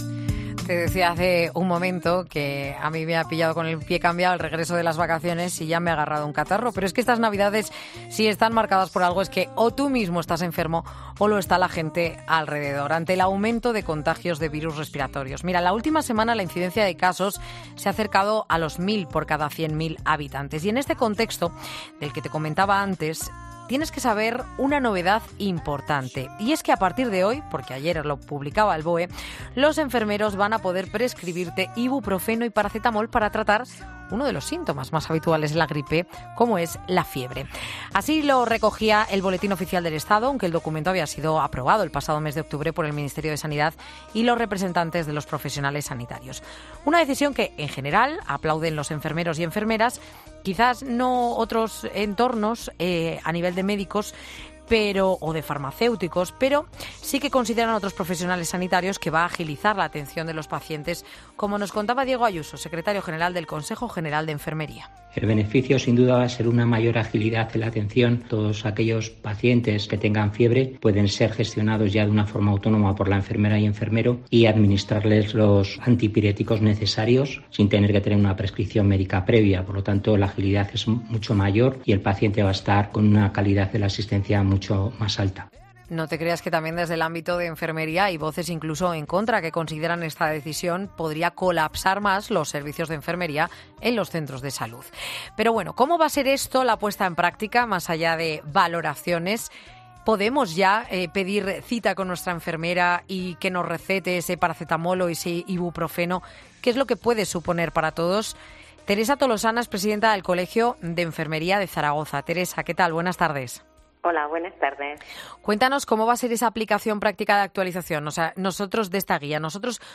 Enfermera